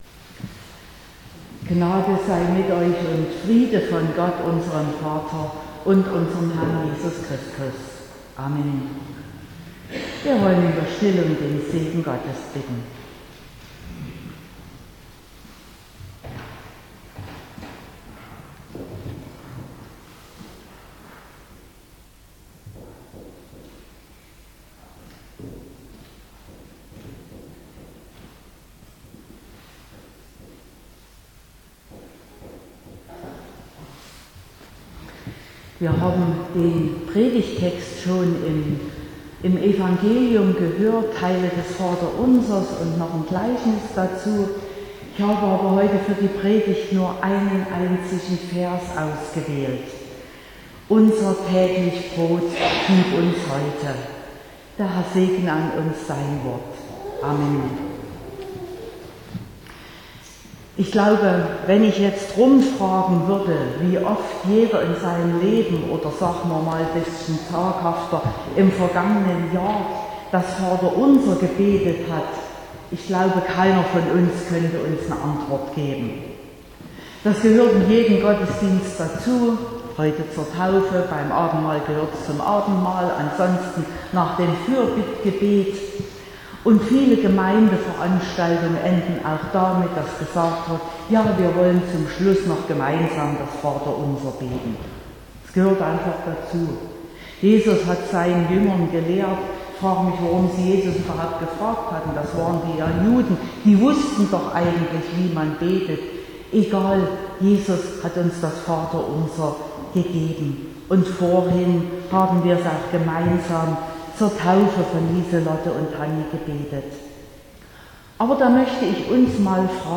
22.05.2022 – Gottesdienst
Predigt und Aufzeichnungen